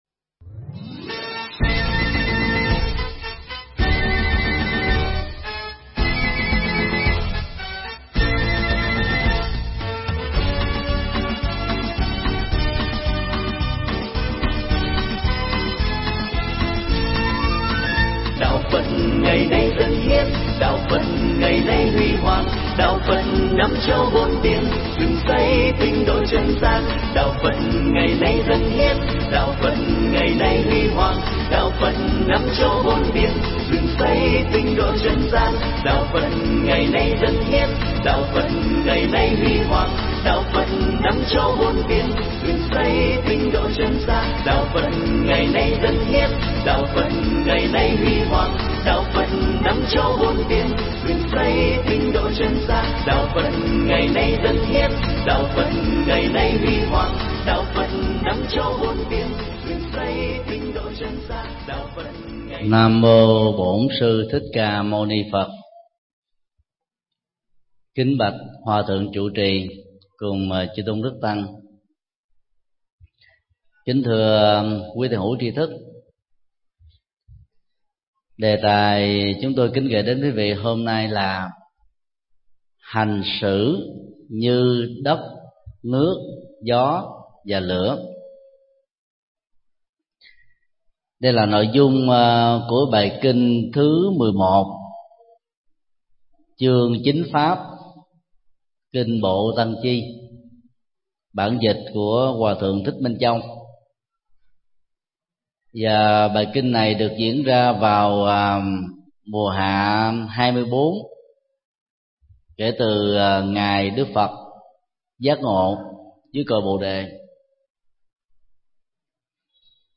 Tải mp3 Bài giảng Hành xử như đất nước gió lửa do thầy Thích Nhật Từ giảng tại chùa Ấn Quang, ngày 29 tháng 04 năm 2012.